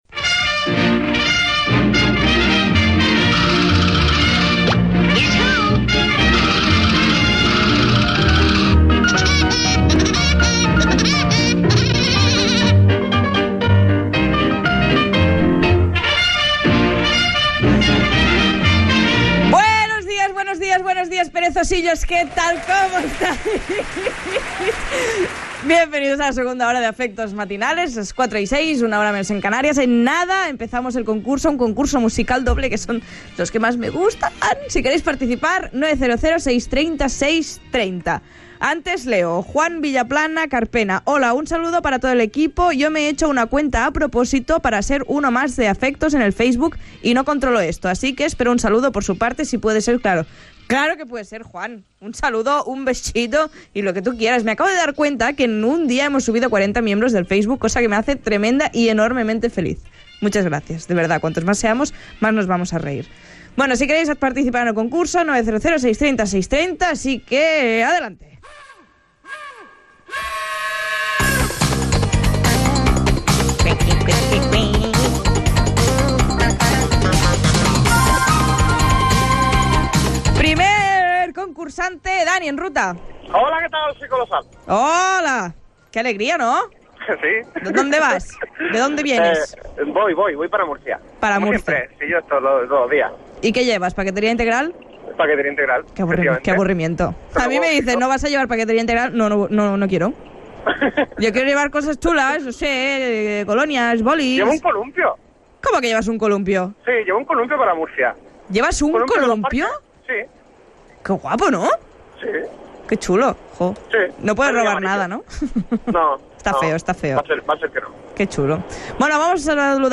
Indicatiu del programa i invitació a participar per acabar una frase. Gènere radiofònic Participació